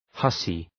Προφορά
{‘hʌsı}